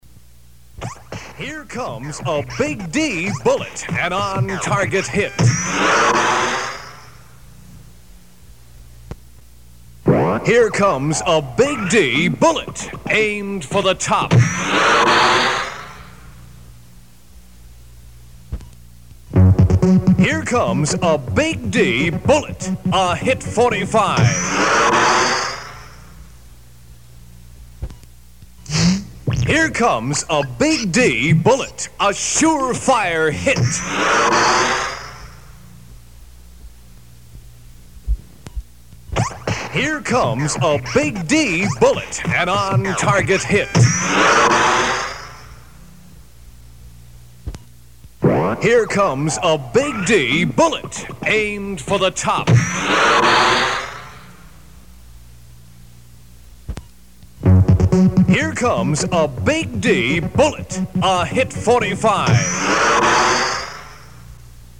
of intros for Big D Bullets